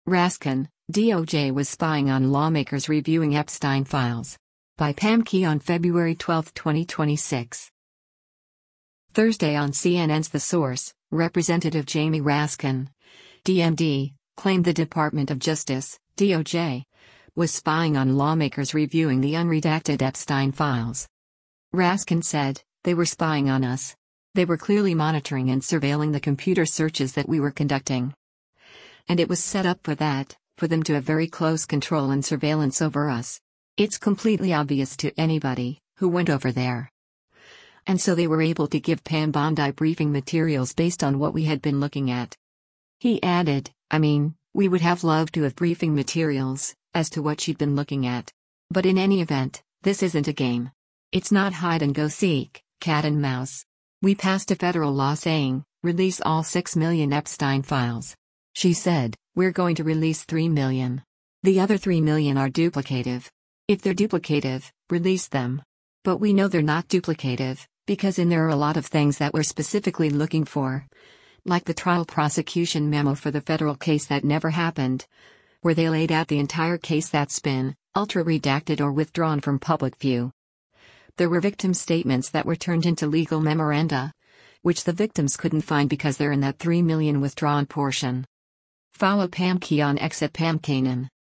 Thursday on CNN’s “The Source,” Rep. Jamie Raskin (D-MD) claimed the Department of Justice (DOJ) was spying on lawmakers reviewing the unredacted Epstein files.